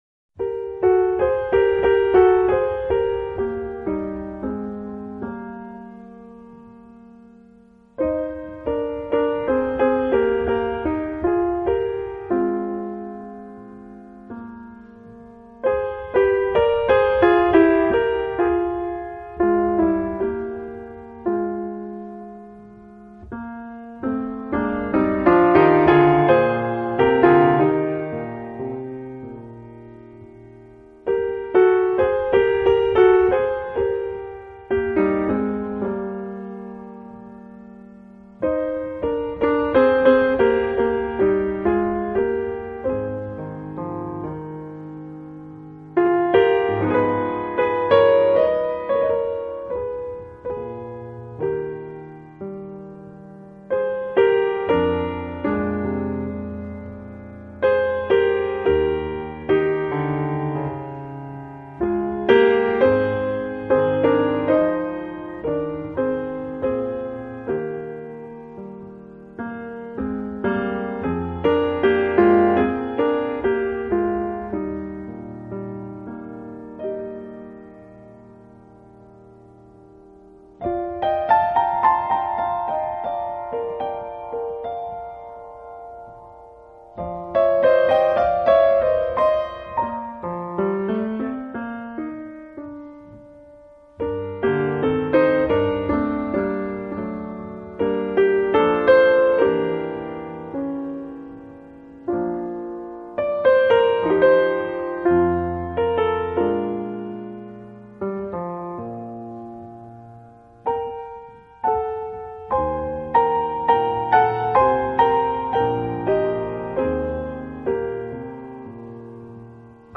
piano
bass
drums
音乐类型：Jazz